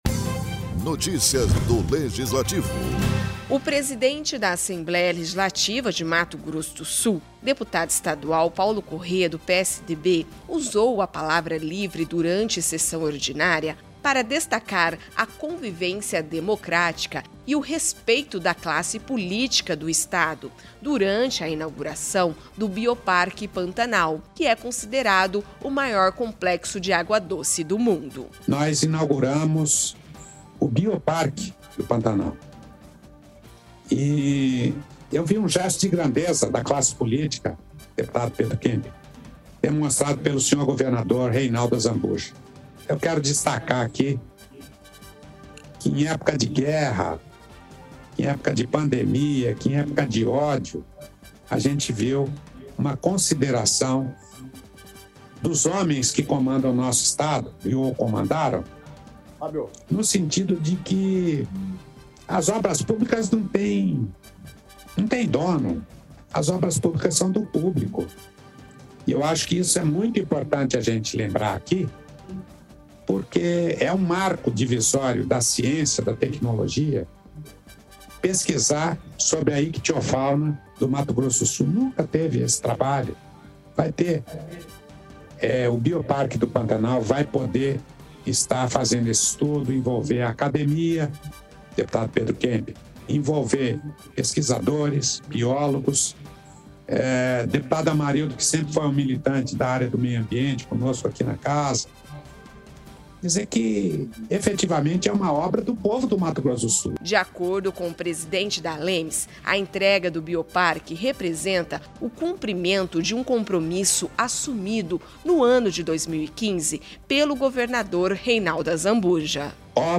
O presidente da Assembleia Legislativa de Mato Grosso do Sul (ALEMS), deputado Paulo Corrêa (PSDB), usou a palavra livre durante sessão ordinária, para falar sobre a convivência democrática e o respeito da classe política do Estado, durante a inauguração do Bioparque Pantanal, que é considerado o maior complexo de água doce do mundo.